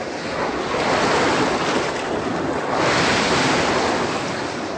Sea.ogg